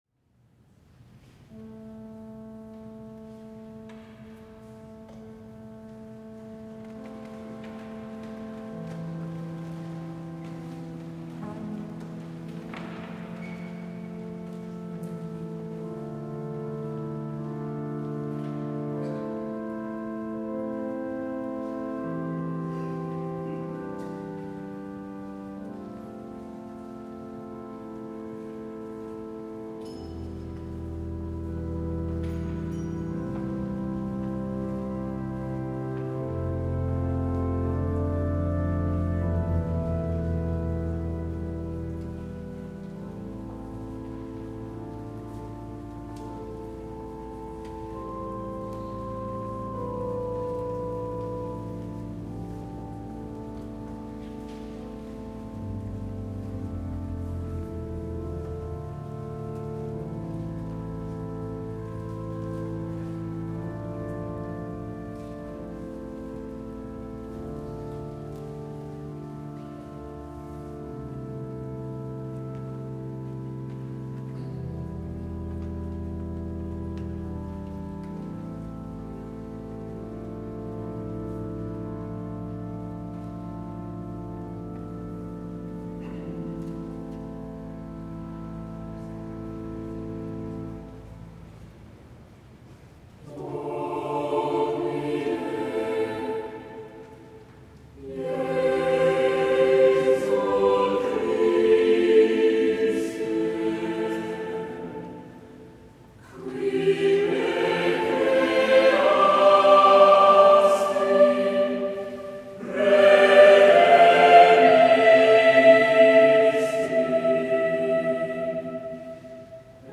6:30 p.m. | Parish House, Andrew Hall